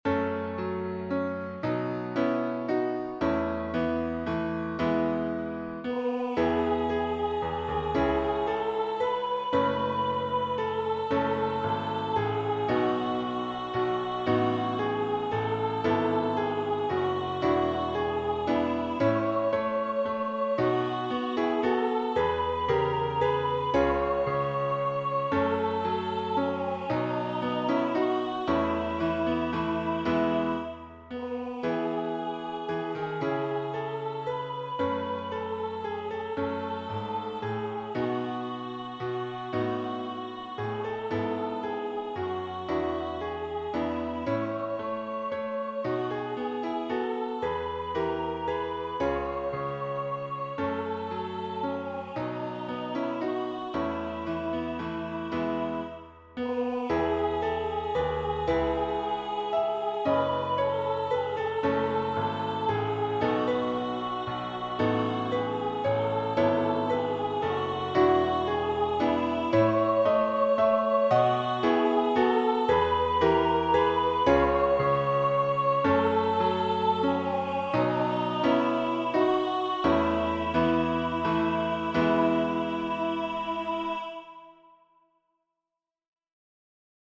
An inspirational morning hymn. Unison, piano accompaniment.